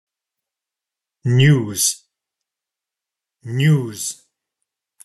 Pronunciation : /njuːz/ Examples of usage: I have just heard the news.